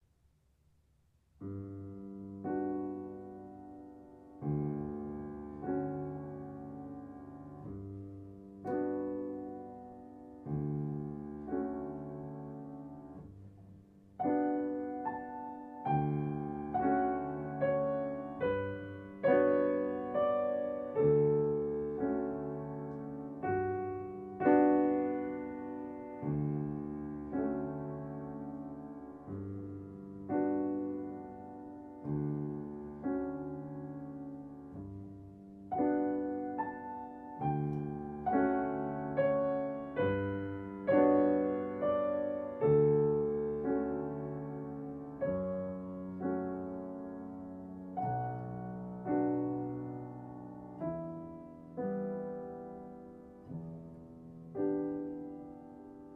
ゆったりの速さと静かに奏でる伴奏的なベース運びと和音が、厳かに染み渡ります
・演奏の速さは♩=６０くらい（ざっくり、レントの速度は４０〜６０あたりの数値で示される）
軽快な速さとはうって変わって、ずっしりと鍵盤の底の感触を味わうようなゆったりの音運びが印象的です。